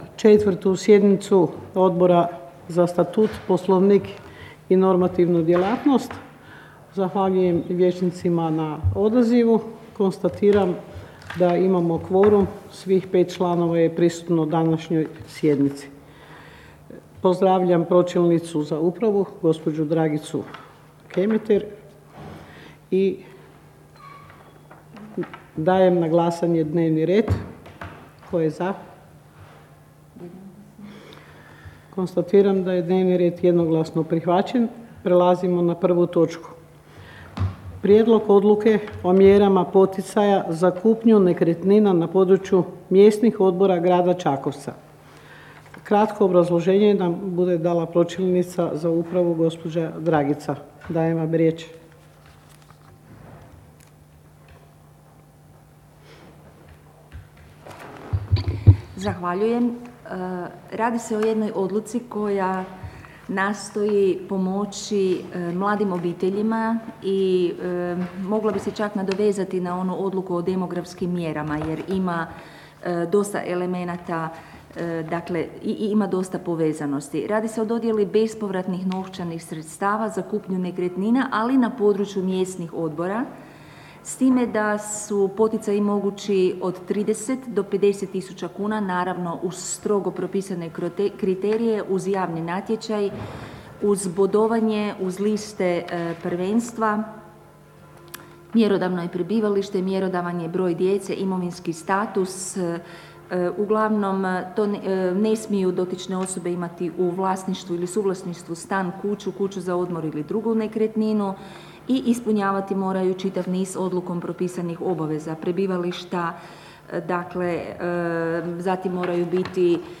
Obavještavam Vas da će se 4. sjednica Odbora za Statut, Poslovnik i normativnu djelatnost Gradskog vijeća Grada Čakovca održati dana 29. ožujka 2022. (utorak), u 12.00 sati, u vijećnici Uprave Grada Čakovca.